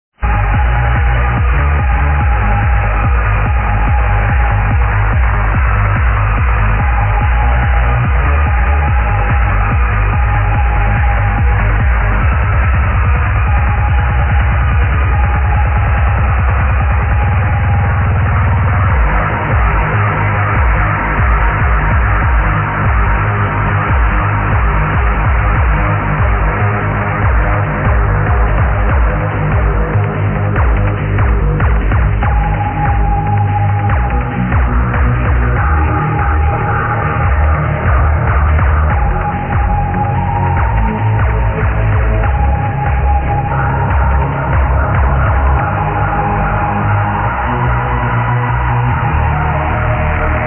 Mind Blowing Trance! plz id!!!! for heavens sake!!
Got that electro buzz sound.